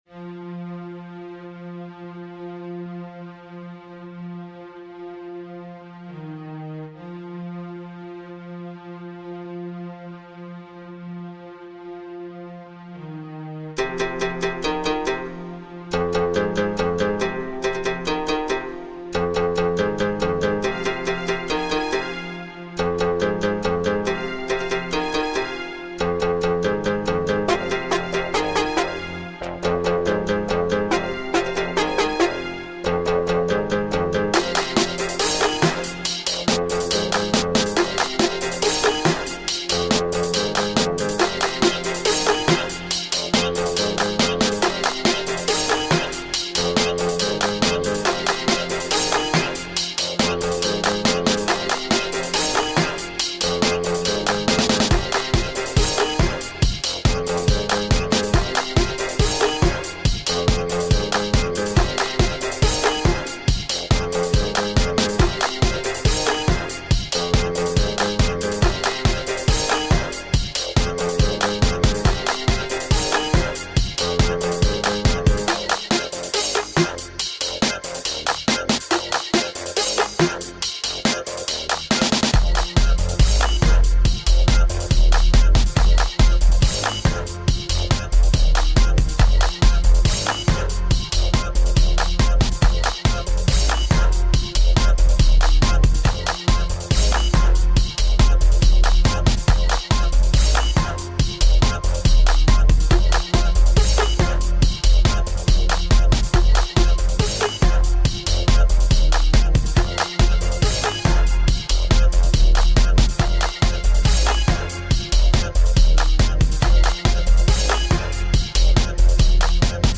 Dubplate style!!!